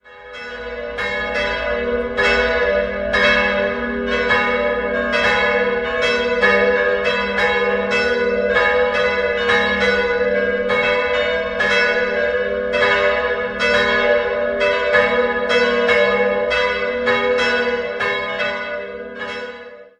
Markant ist der Spitzturm mit seinem bunt glasierten Ziegeldach. 3-stimmiges TeDeum-Geläute: gis'-h'-cis'' Die beiden größeren Glocken wurden 1922 von der Gießerei Schilling in Apolda gegossen. 1959 kam eine dritte Glocke von Friedrich Wilhelm Schilling dazu.